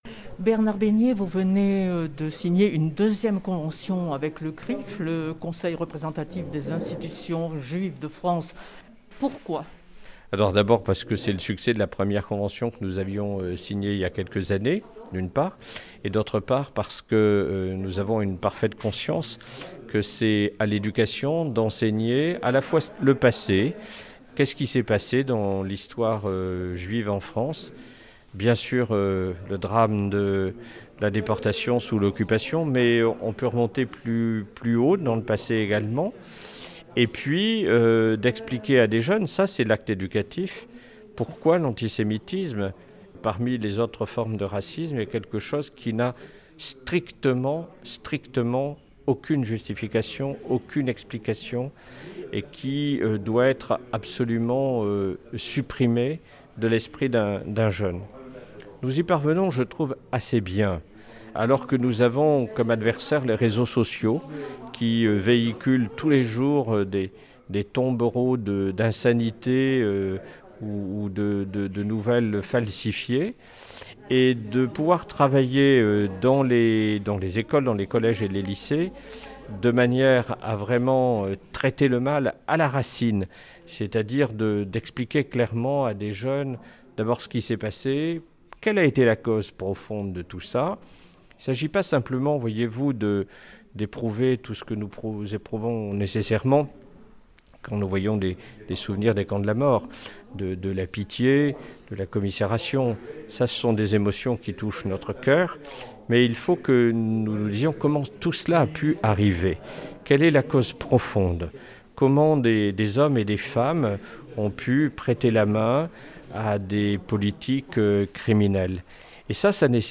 A son tour, Bernard Beignier, Recteur de la région académique Provence-Alpes-Côte d’Azur, Recteur de l’académie d’Aix-Marseille, Chancelier des universités le recteur revient sur le bien fondé de cette convention de partenariat. Entretien.